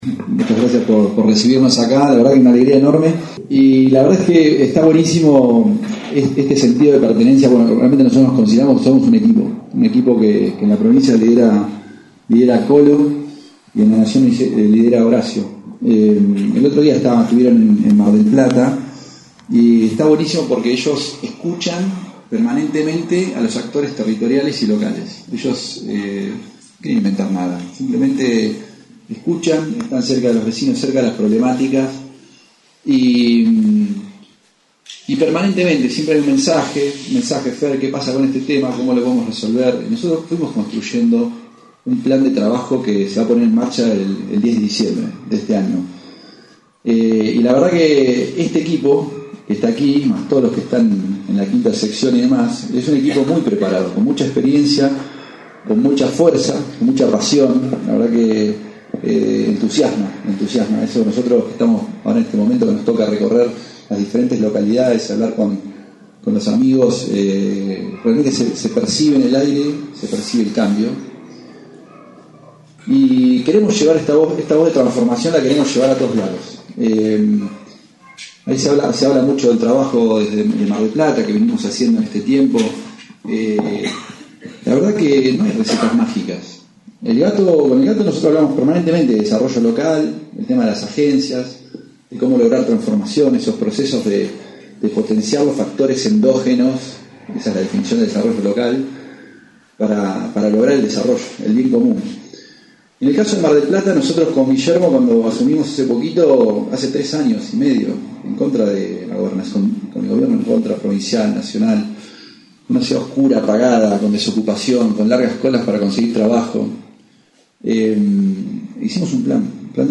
Con la presencia de distintos dirigentes y pre candidatos de la 5ta sección electoral, se inauguró el bunker electoral en la ex panadería Chateláin en calle San Martin al 146.